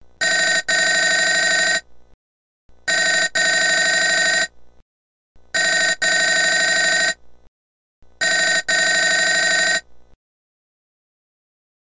Звук телефона, четыре двойных звонка